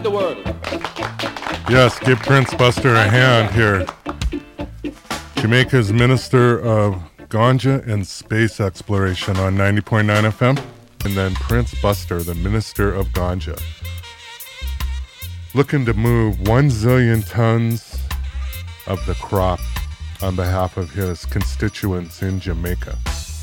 ’80 UK ska live